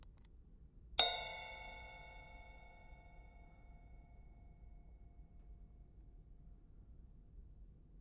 JP现场录音 " 铙钹3
描述：在我的房子里用现场录音机录制的铙钹